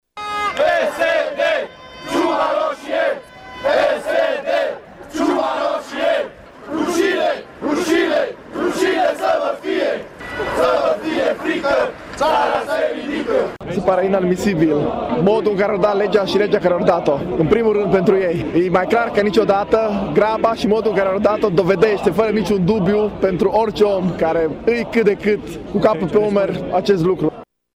Și la Tg.Mureș aproximativ 1500 de persoane au ieșit să își exprime nemulțumirea scandând lozinci împotriva Guvernului și a conducerii PSD.